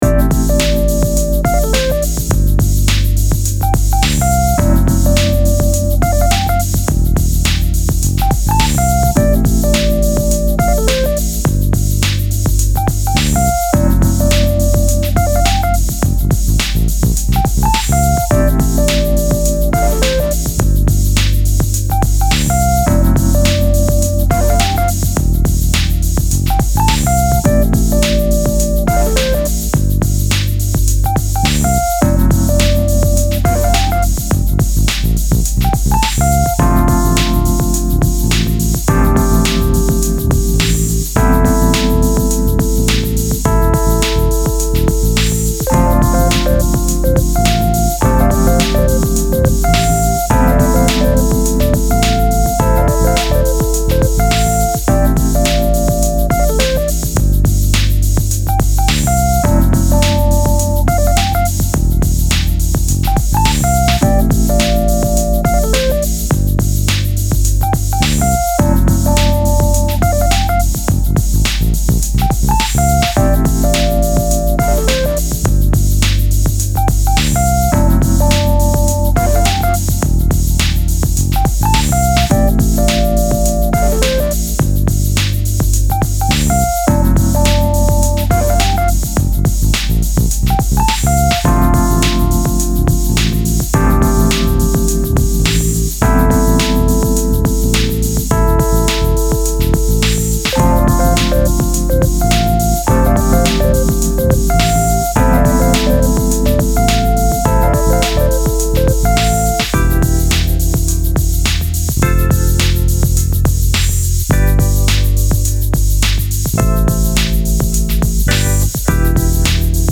Style Style EDM/Electronic
Mood Mood Cool, Relaxed
Featured Featured Bass, Drums, Mellotron +2 more
BPM BPM 105